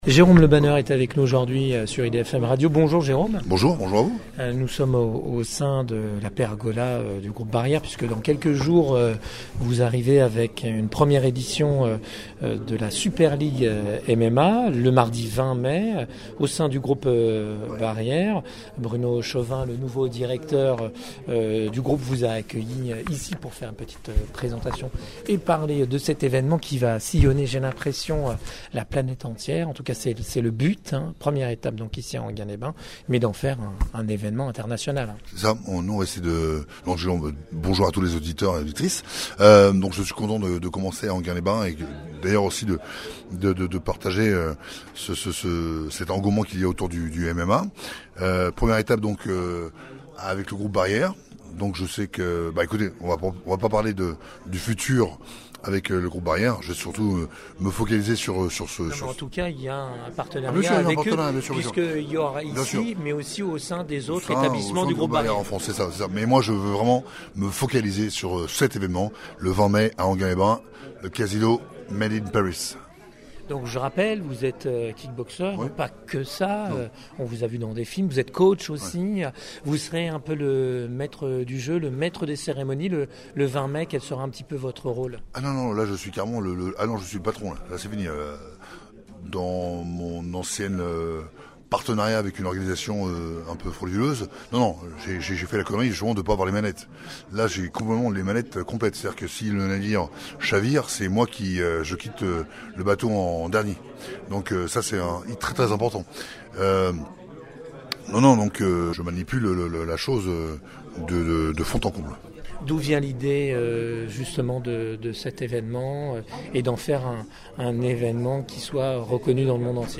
Mais le mieux placé pour vous en parler est bien sûr le fondateur lui-même, Jérôme Le Banner, on vous invite donc à l’écouter pour en savoir plus.
Itw-LE-BANNER-Bon.mp3